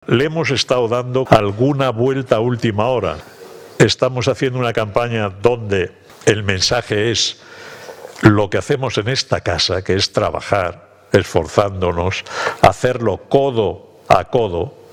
“Estamos haciendo una campaña donde el mensaje es lo que hacemos en esta casa, que es trabajar, hacerlo codo a codo”, explicaba el presidente del Grupo Social ONCE,